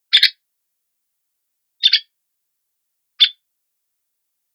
Veniliornis spilogaster - Carpintero manchado
carpinteromanchado.wav